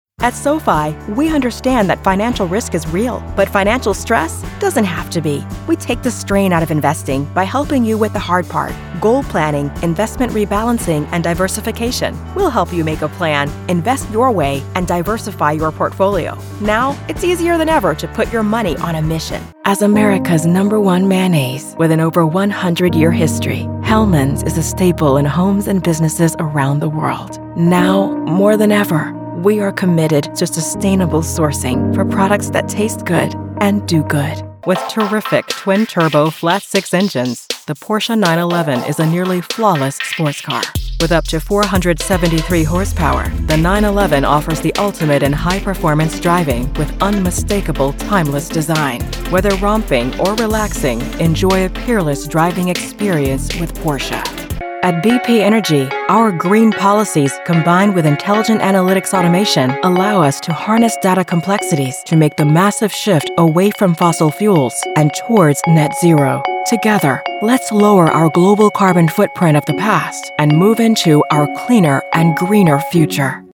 Female corporate narration voice over actor